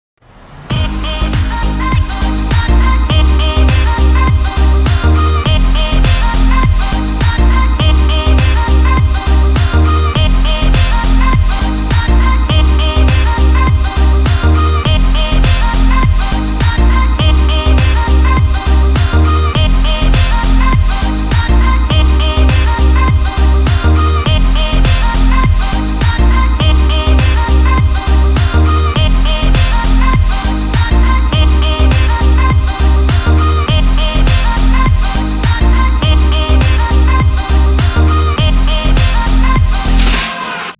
ритмичные
веселые
Electronic
без слов
инструментальные